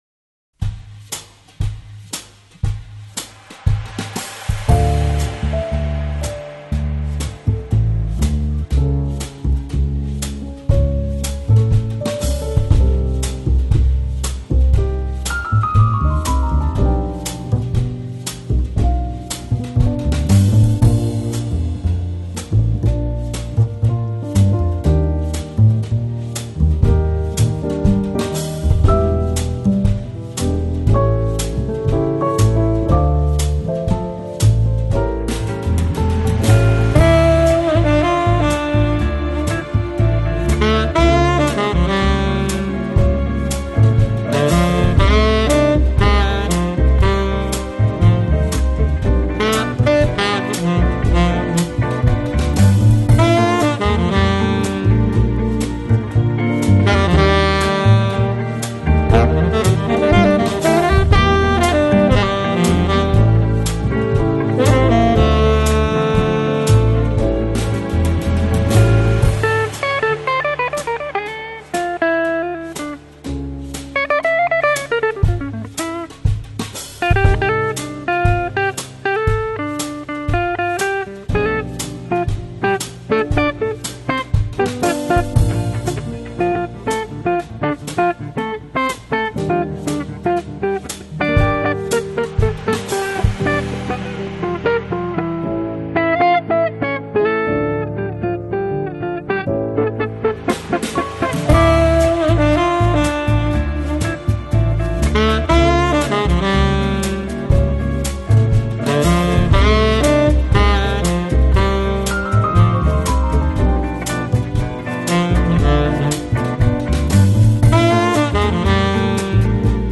Жанр: Lounge, Chillout, Funk, Jazz, Electronic